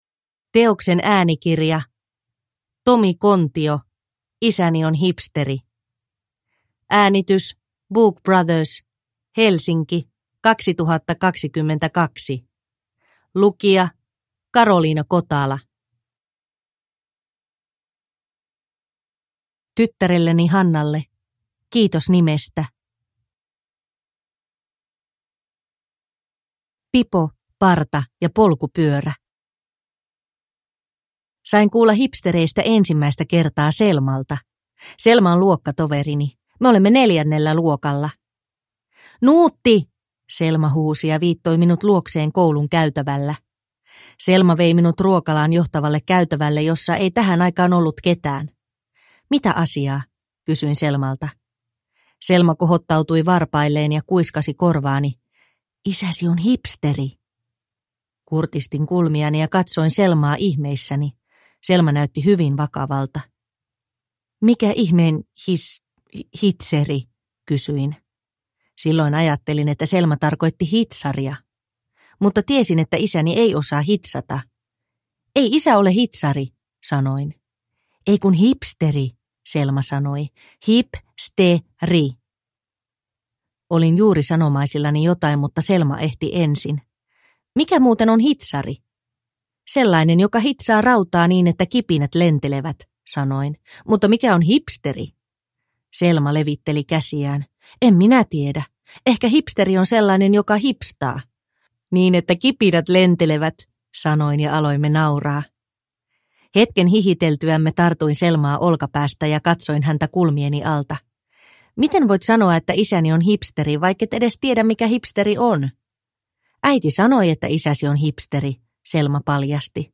Isäni on hipsteri – Ljudbok – Laddas ner